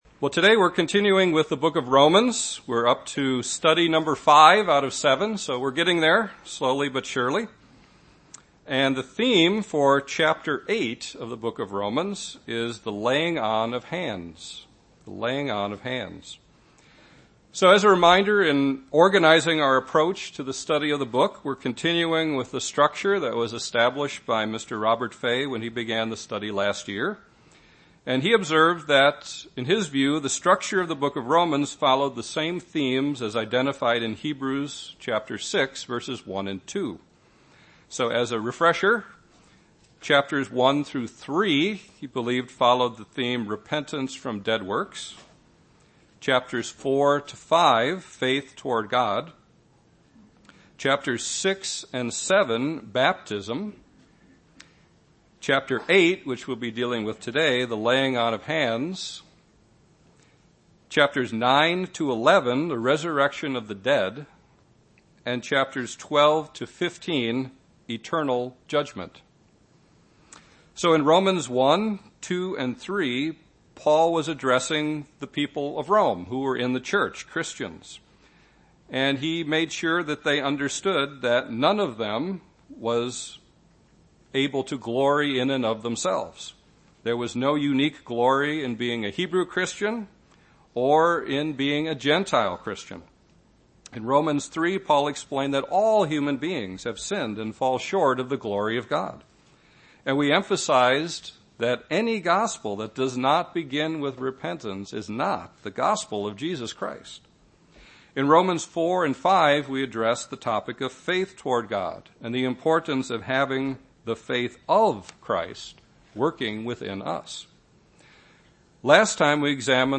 Given in Chicago, IL
UCG Sermon Romans Hebrews 6 Holy Spirit Studying the bible?